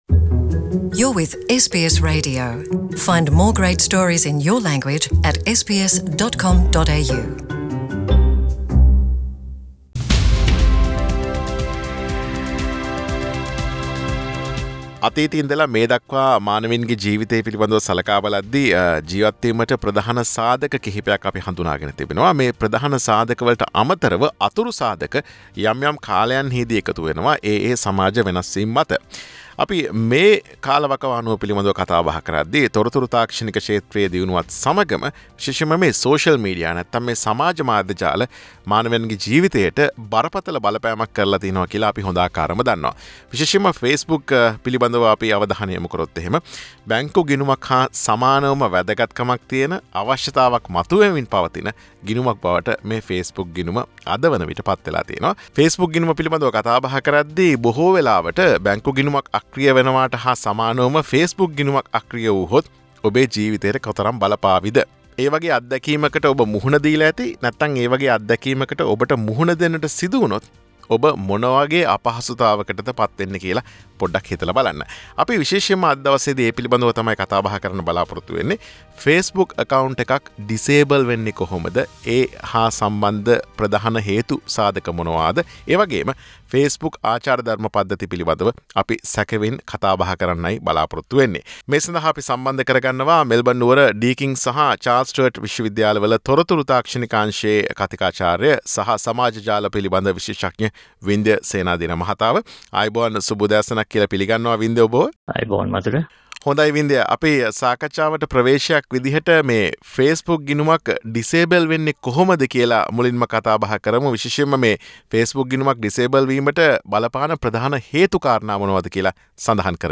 සාකච්ඡාව